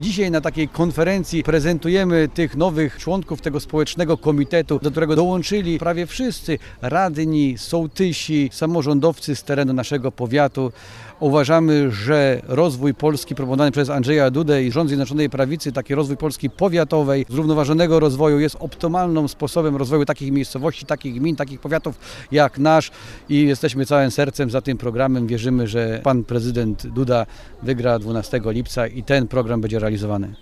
Członkowie i sympatycy Prawa i Sprawiedliwości spotkali się z mieszkańcami Szlichtyngowej w ramach akcji „Łączy nas Polska”. To kampania prezydencka zachęcająca do głosowania na Andrzeja Dudę.
– Prezentujemy dziś członków Powiatowego Komitetu Poparcia dla Andrzeja Dudy, wśród których są między innymi lokalni samorządowcy – powiedział Andrzej Bielawski, wschowski starosta: